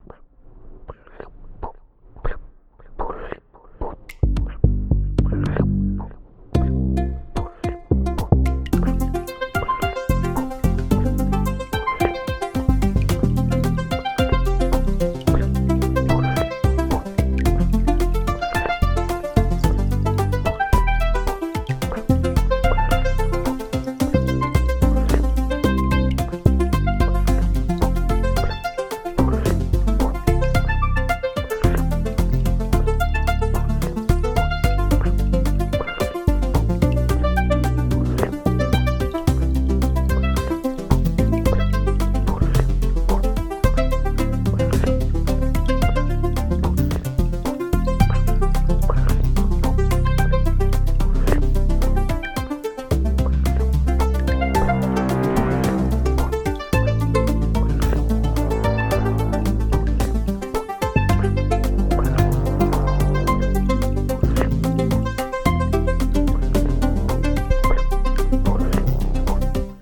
I kind of meant this to sound like you're riding a hoverbike around but I think it ended up more like a pirate shanty???